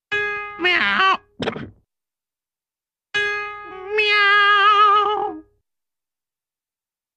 Cat Tuning Up with 'meow' ( Sour ) Then Throat Clear, Followed By Musical Meow